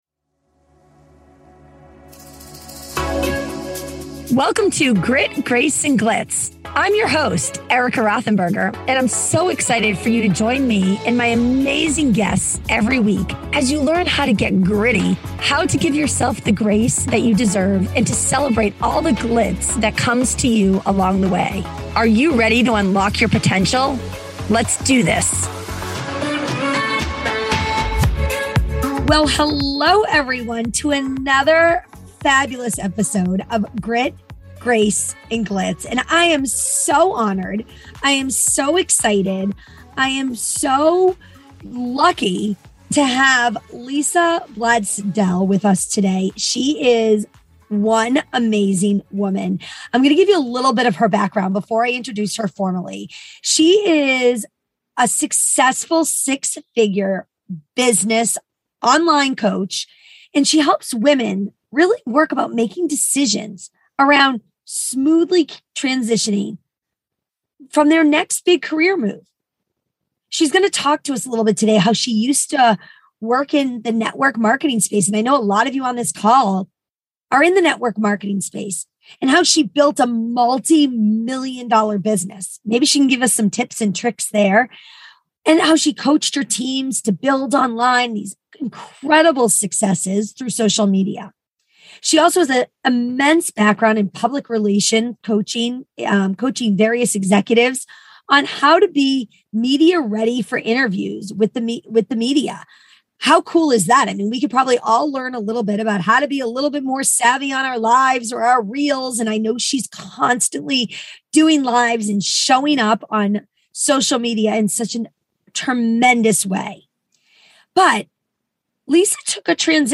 This candid conversation will move to you be more open, more vulnerable and more YOU than ever before.